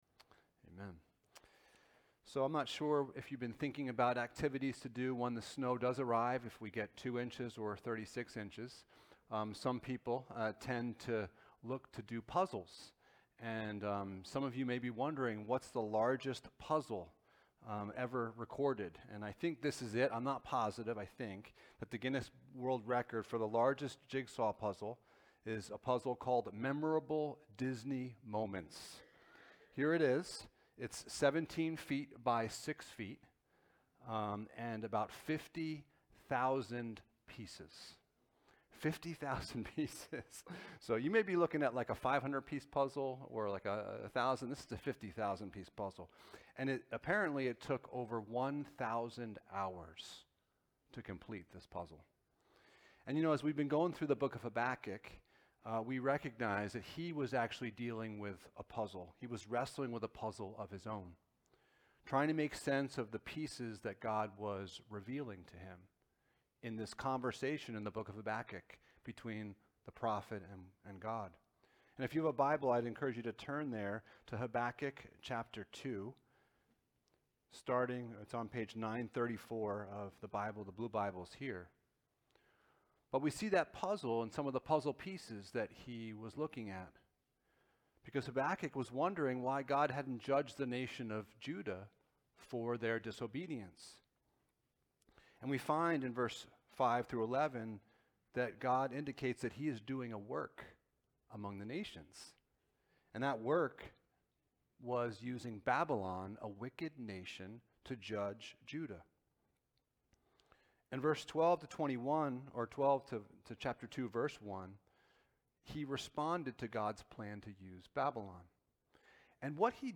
Passage: Habakkuk 2:2-20 Service Type: Sunday Morning